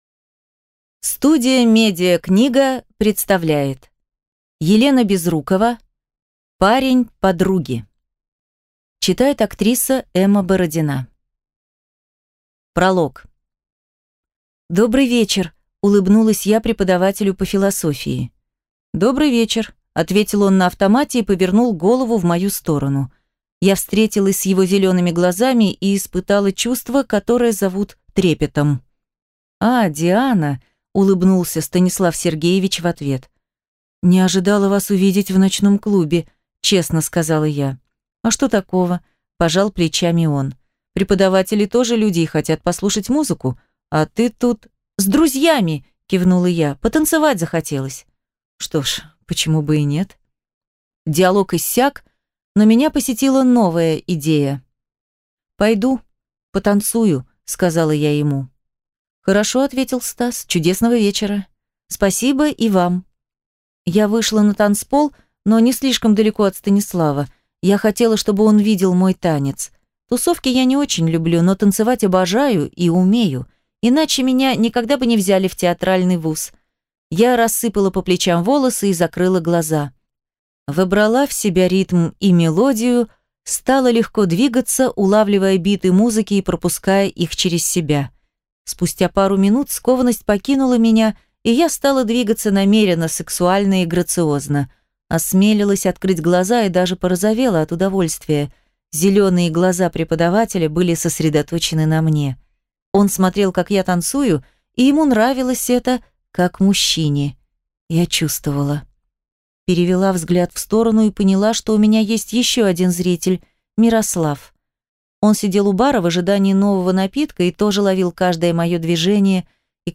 Аудиокнига Парень подруги | Библиотека аудиокниг